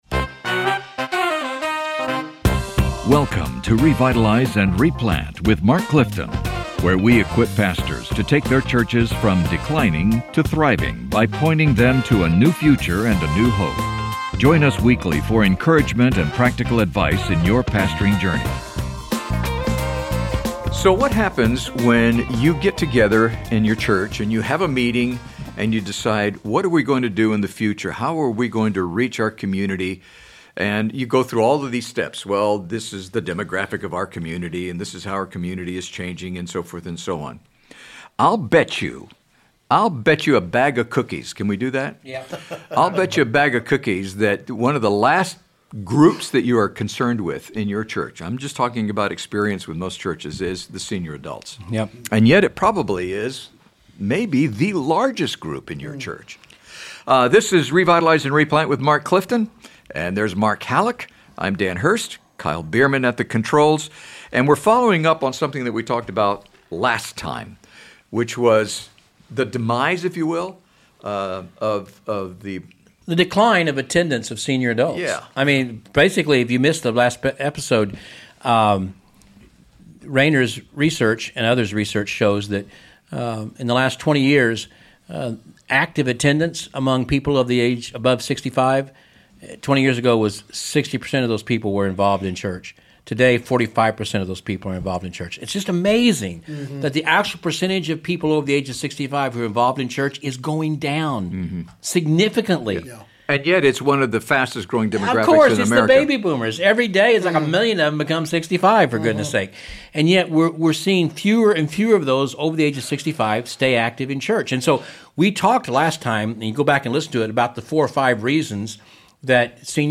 As many congregations face aging memberships and declining attendance among older adults, this conversation offers actionable steps to help church leaders reconnect with, value, and retain senior adults as vital members of the body of Christ.